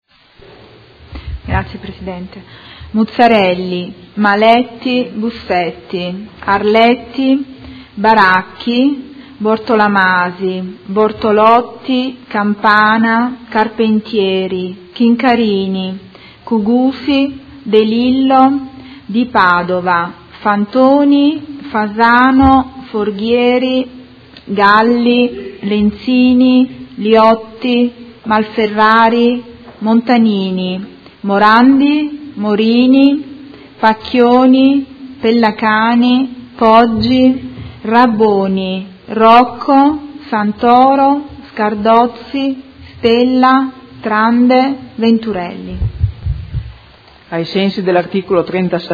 Seduta del 6 ottobre. Appello
Segretario Generale